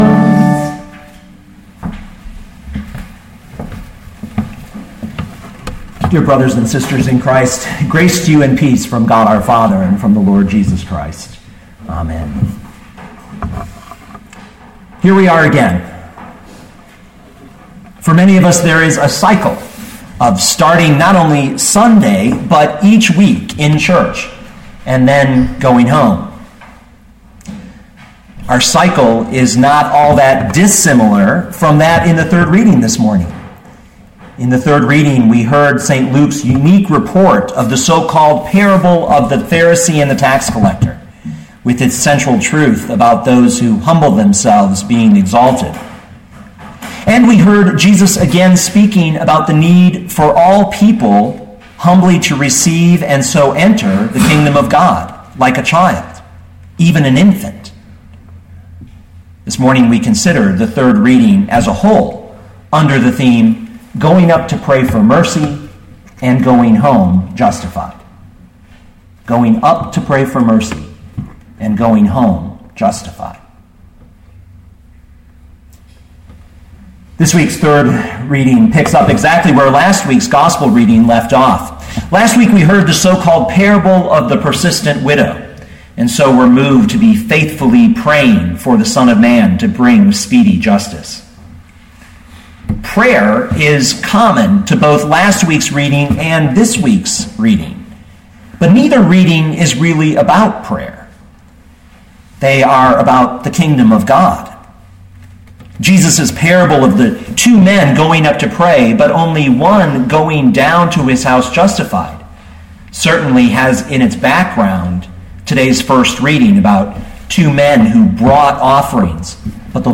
2013 Luke 18:9-17 Listen to the sermon with the player below, or, download the audio.